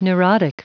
Prononciation du mot neurotic en anglais (fichier audio)
neurotic.wav